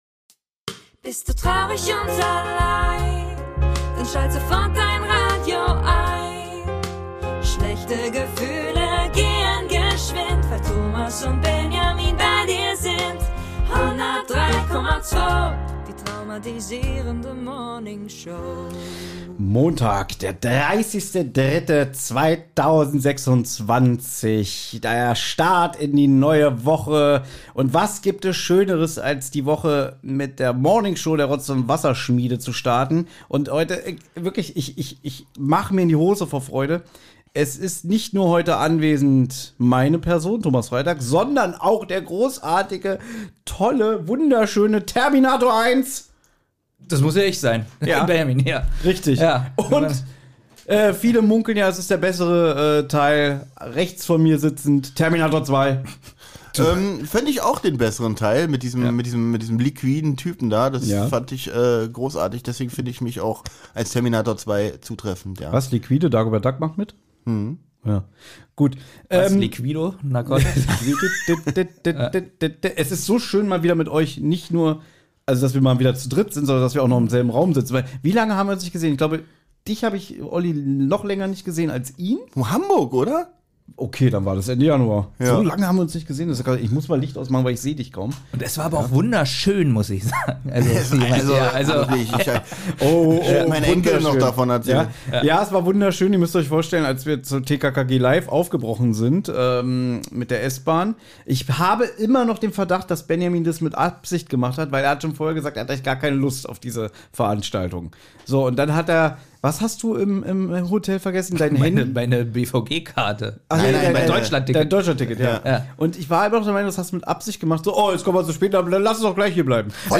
Die drei Podcaster haben es doch tatsächlich geschafft, sich mal wieder gemeinsam in einem Raum zu versammeln und eine Morningshow aufzunehmen!